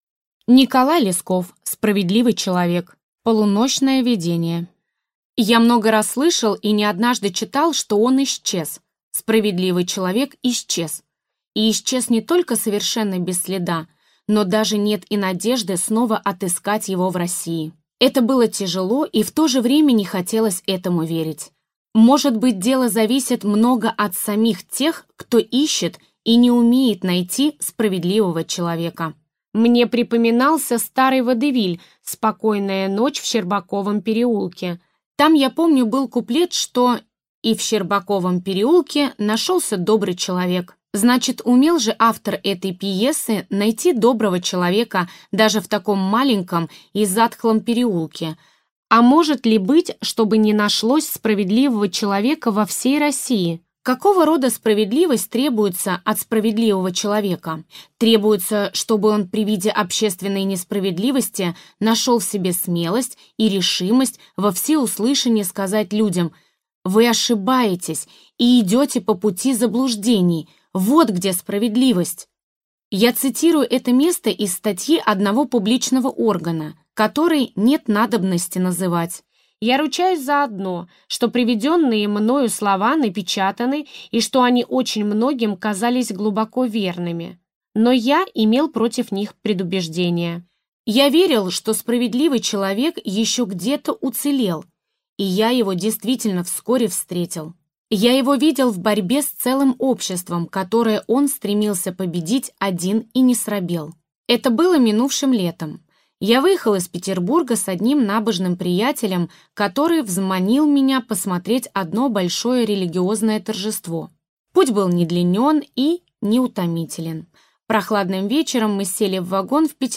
Аудиокнига Справедливый человек | Библиотека аудиокниг